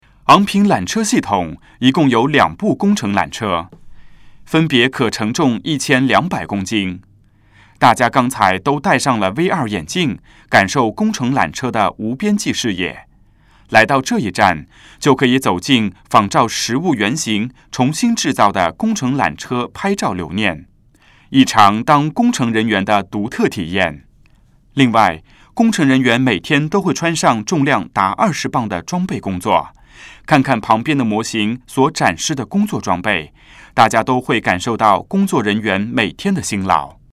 缆车探知馆语音导赏 (普通话)